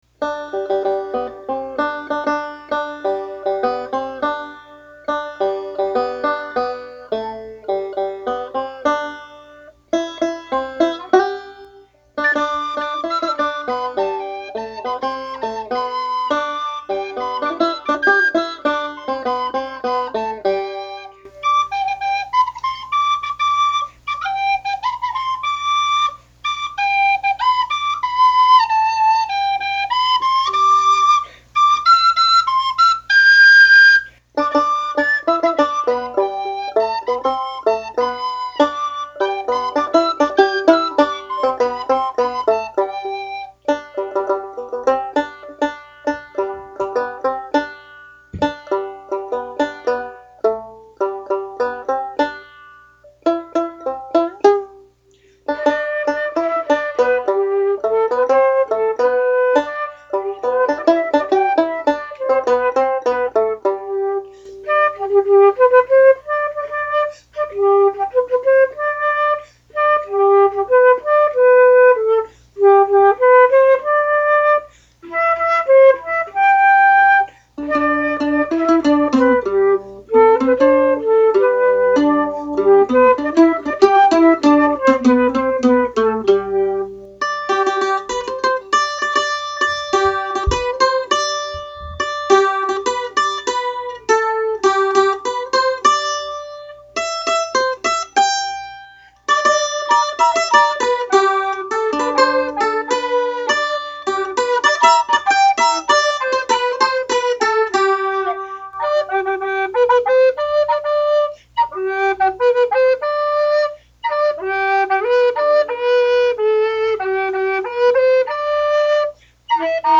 banjo, tin whistle, homemade fretless banjo, flute, mandolin, bass recorder, and a banjo with a skin head. We played in the key of G, and this recording is a single take.
Words and music: Traditional Appalachian